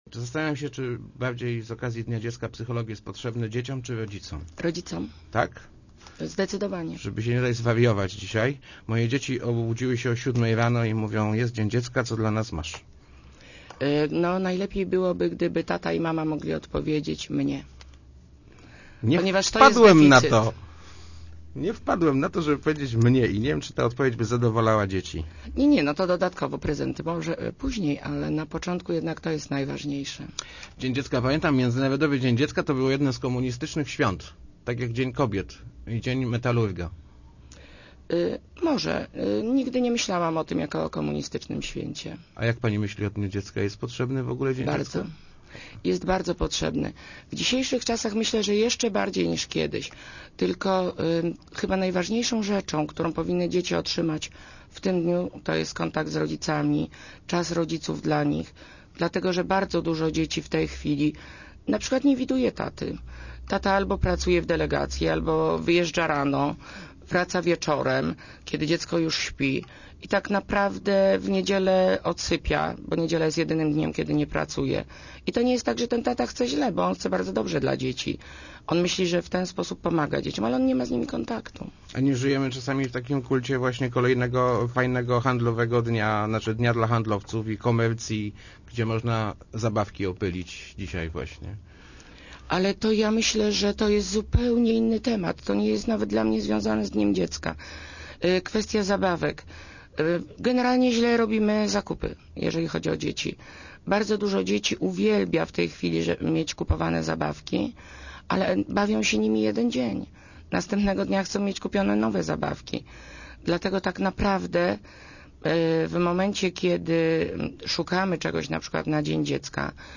Wiadomości